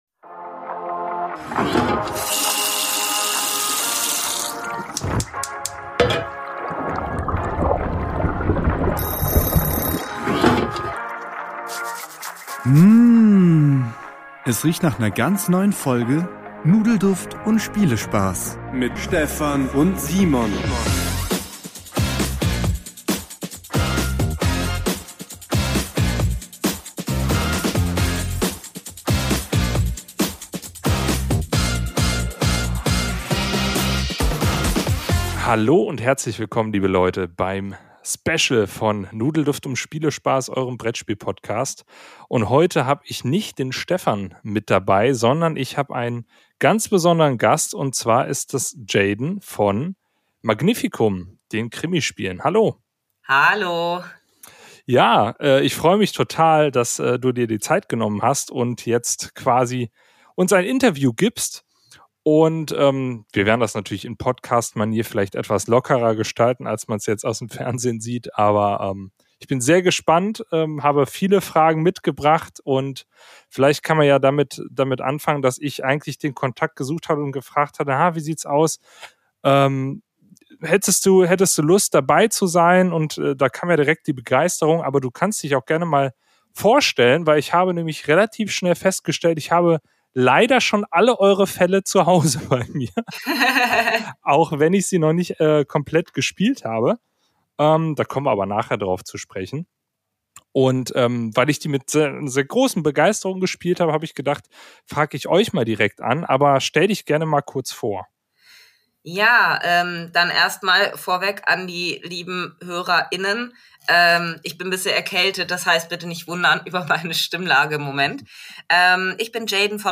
Interview mit Magnificum Krimispiele ~ Nudelduft & Spielespaß - Der Brettspiel Podcast